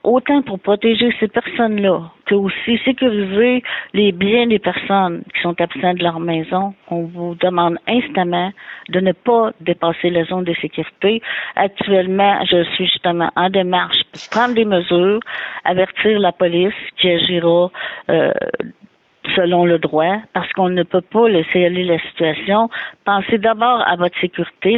En entrevue, la mairesse, Denise Gendron, ignorait toutefois pour combien de temps ils pourront recevoir cette aide.